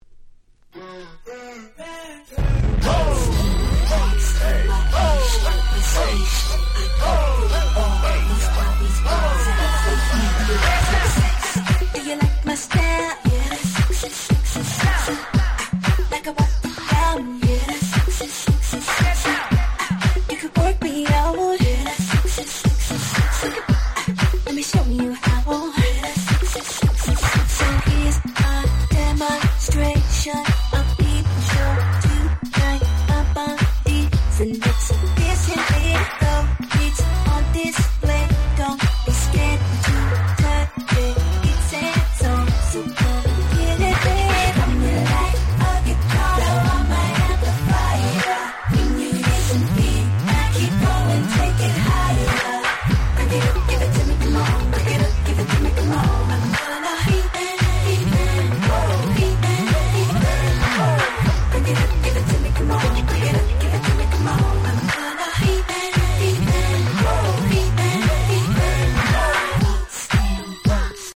08' Smash Hit R&B !!
どのRemixもフロア映えする即戦力なRemixです！